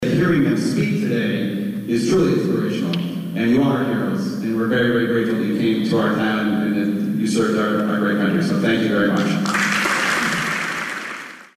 Duxbury High School held a ceremony Wednesday paying tribute to two Medal of Honor winners.
State Representative Josh Cutler joined his colleagues Rep. Kathy LaNatra and State Senator Patrick O’Connor on stage to present a citation to the two men.